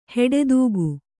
♪ heḍedūgu